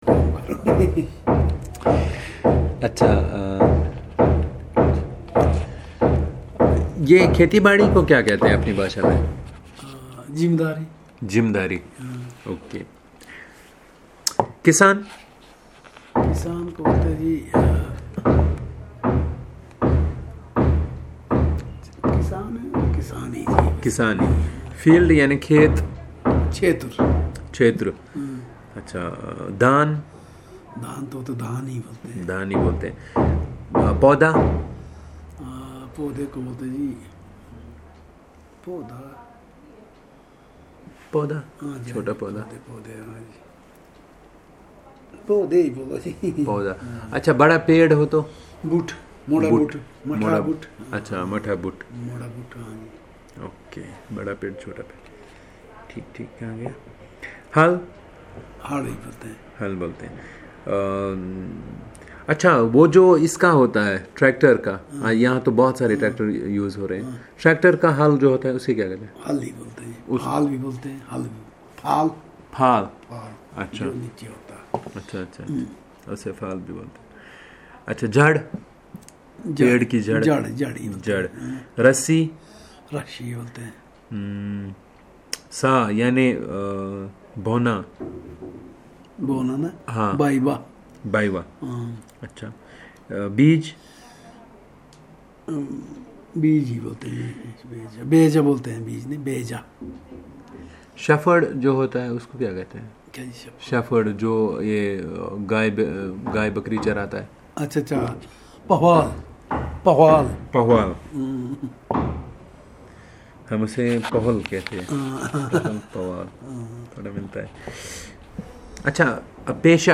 Elicitation of words about professions